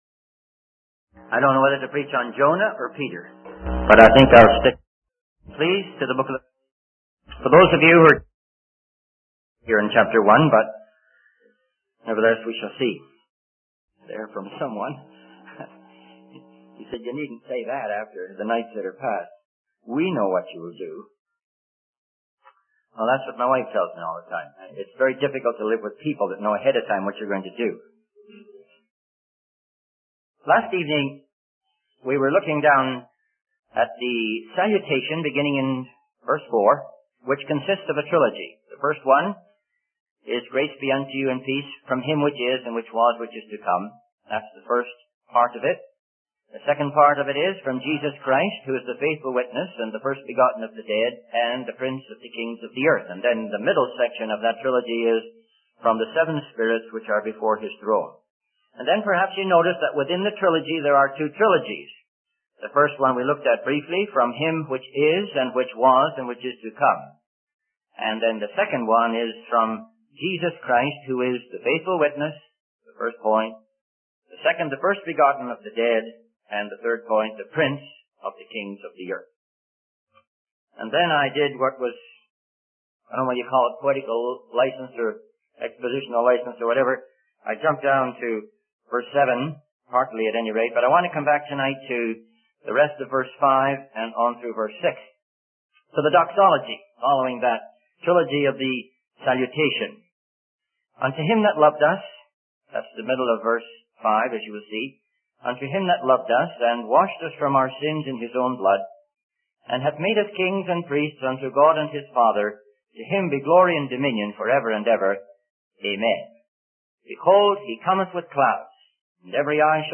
In this sermon, the speaker reflects on the fleeting nature of worldly events and the constant search for something that can capture people's attention. He emphasizes that nothing in the world can hold people's attention for long, but there is something that will capture their attention: the second coming of Jesus Christ.